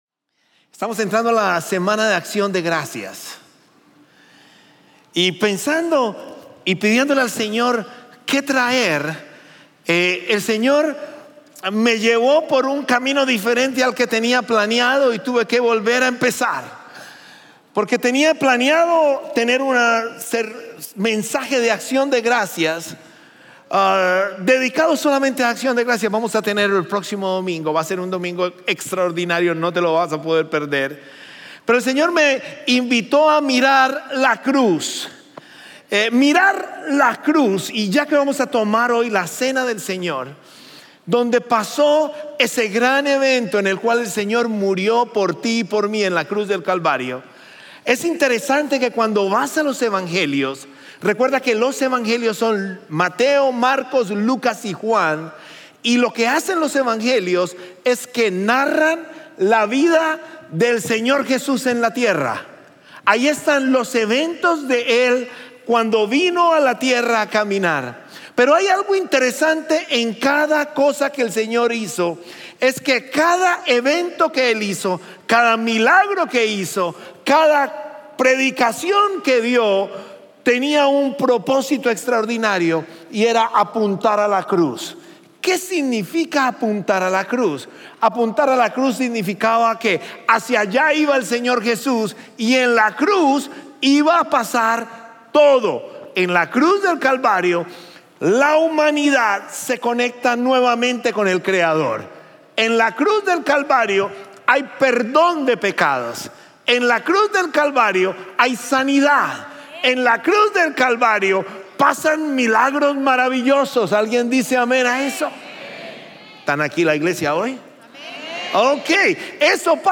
Series de Sermones – Media Player